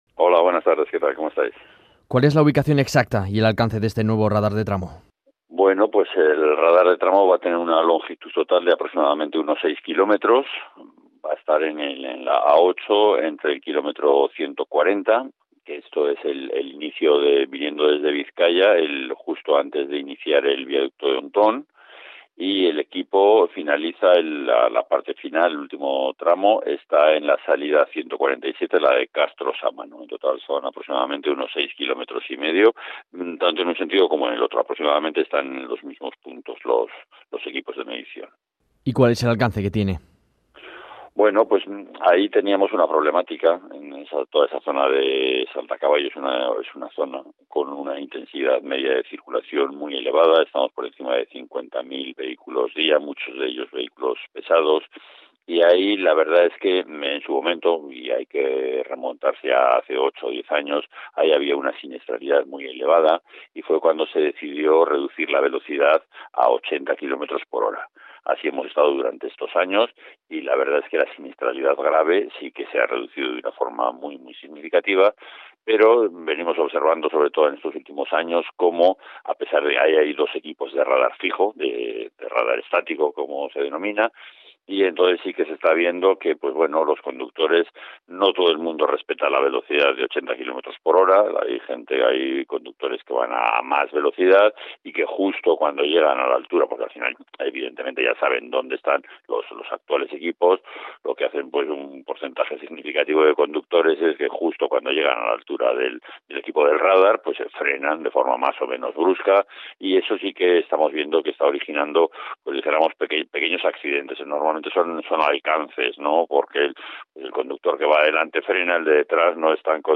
José Miguel Tolosa, Jefe Provincial de Tráfico en Cantabria, ha explicado en una entrevista concedida a la Cadena SER que el radar cubre un tramo de aproximadamente 6,5 kilómetros, entre el kilómetro 140 y la salida 147 de la autovía, en ambos sentidos.